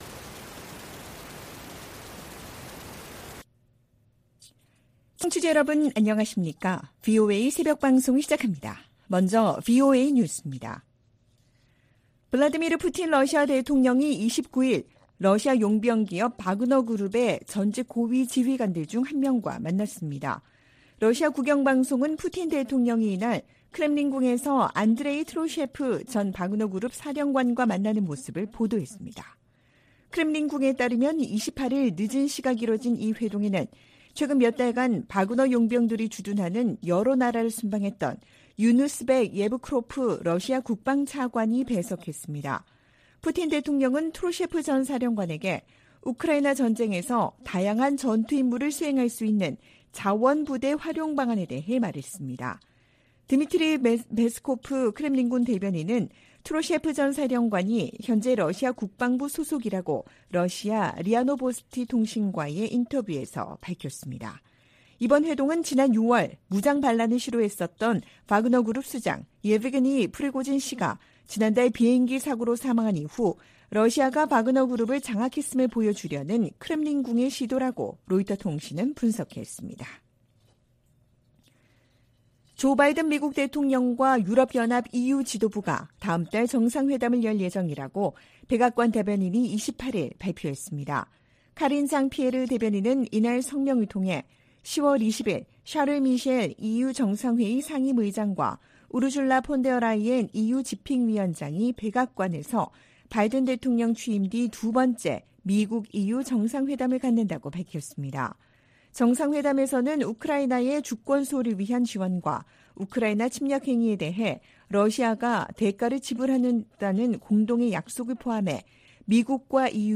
VOA 한국어 '출발 뉴스 쇼', 2023년 9월 30일 방송입니다. 미 국무부는 북한의 핵무력 정책 헌법화를 비판하고, 평화의 실행 가능 경로는 외교뿐이라고 지적했습니다. 미 국방부는 북한이 전쟁의 어떤 단계에서도 핵무기를 사용할 수 있으며, 수천 톤에 달하는 화학 물질도 보유하고 있다고 밝혔습니다.